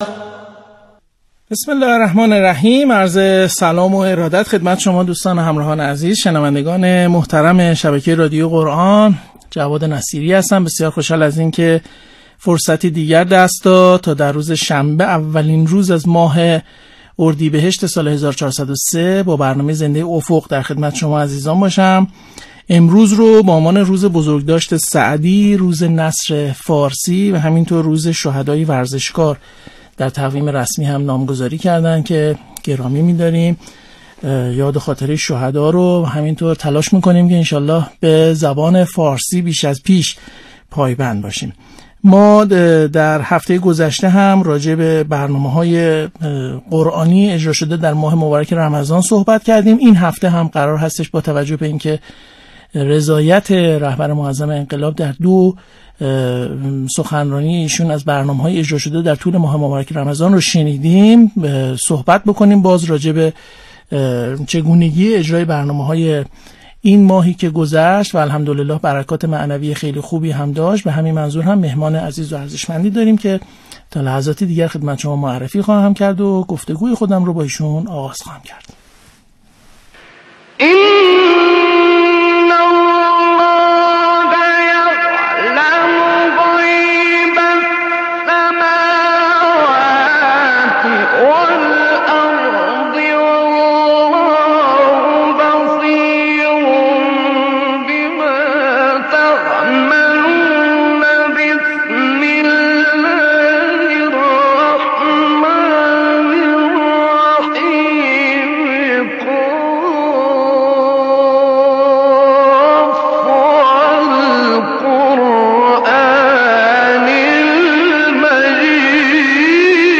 دبیر شورای توسعه فرهنگ قرآنی در بخش دیگری از صحبت‌های خود در این برنامه رادیویی در مورد حضور دستگاه‌ها در این طرح، بیان کرد: یکی از مجموعه‌هایی که امسال خیلی قوی همراه بود، مجموعه سازمان تبلیغات اسلامی بود که با ازخود گذشتگی تمام پای کار آمد، ما از ابتدا عهدی داشتیم که هیچ کدام از مشارکت‌کنندگان دغدغه نام و نشان و تعصبات سازمانی که مانع بسیاری از فعالیت‌های هم‌افزا هست را نداشته باشند.